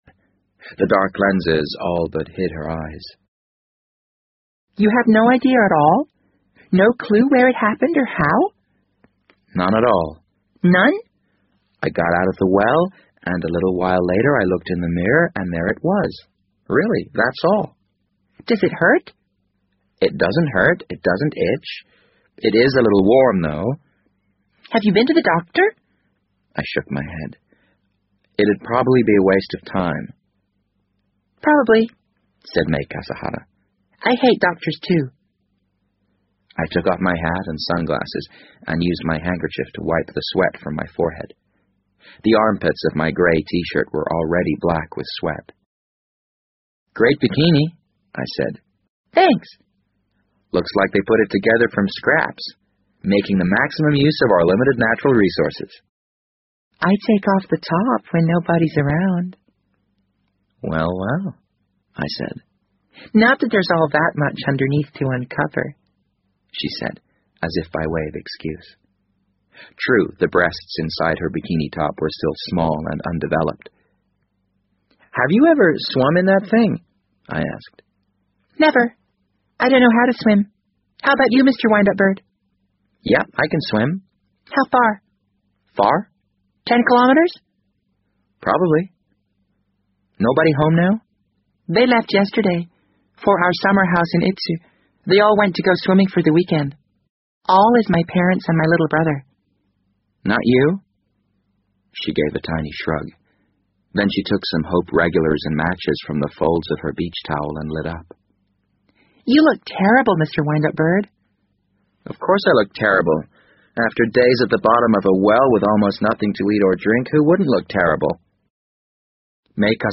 BBC英文广播剧在线听 The Wind Up Bird 008 - 16 听力文件下载—在线英语听力室